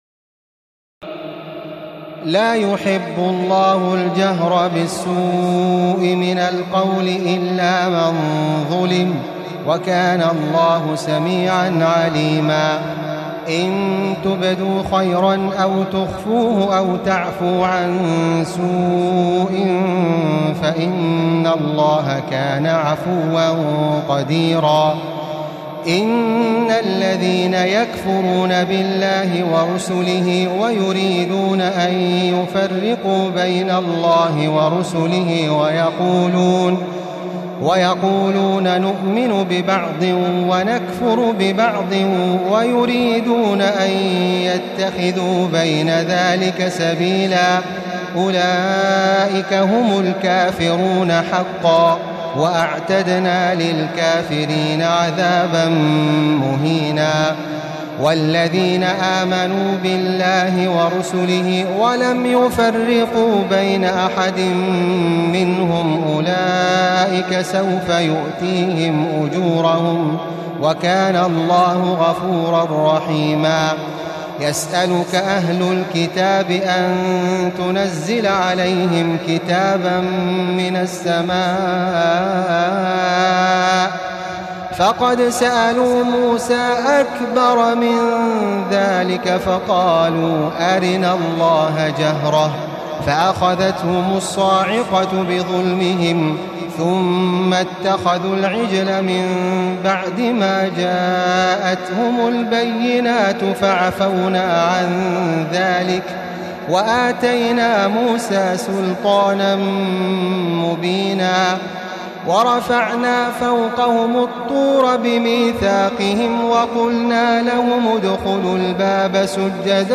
تراويح الليلة السادسة رمضان 1435هـ من سورتي النساء (148-176) و المائدة (1-26) Taraweeh 6 st night Ramadan 1435H from Surah An-Nisaa and AlMa'idah > تراويح الحرم المكي عام 1435 🕋 > التراويح - تلاوات الحرمين